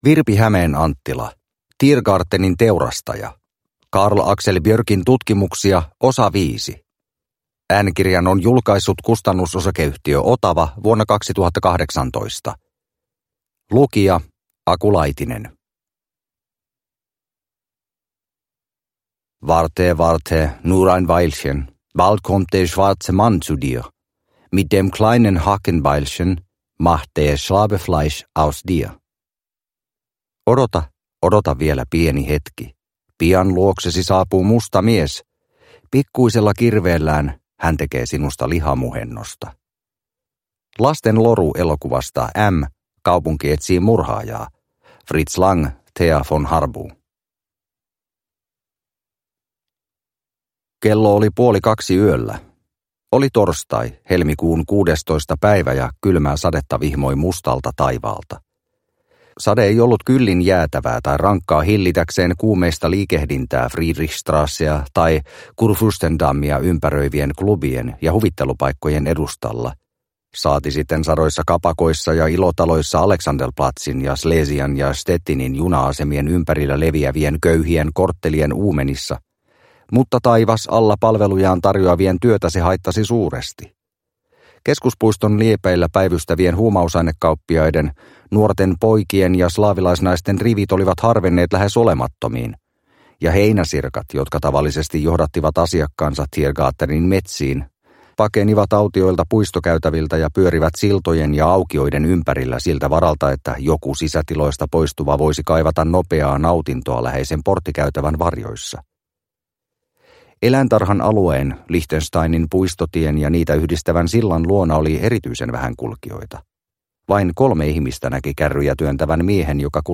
Tiergartenin teurastaja – Ljudbok – Laddas ner